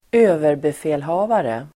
Ladda ner uttalet
överbefälhavare substantiv, supreme commander , commander-in-chief Uttal: [²'ö:verbefä:lha:vare] Böjningar: överbefälhavaren, överbefälhavare, överbefälhavarna Definition: chef för ett lands krigsmakt